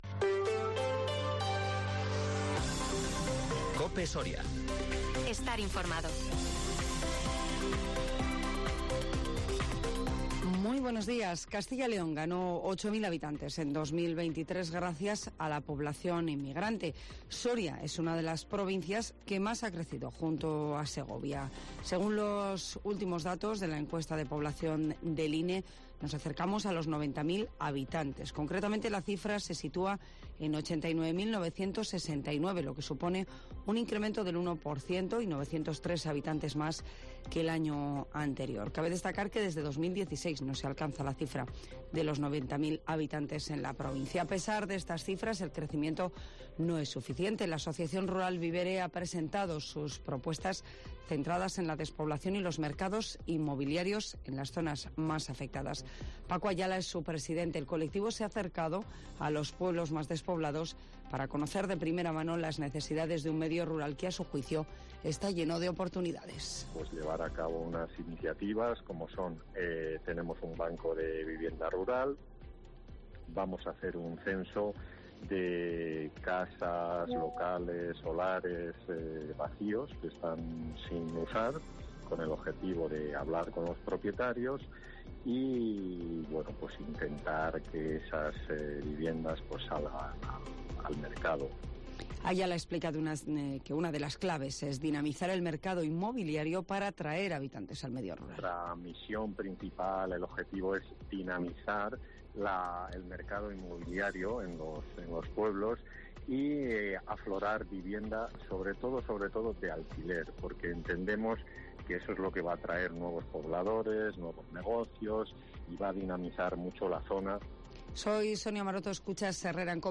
AUDIO: Las noticias en COPE Soria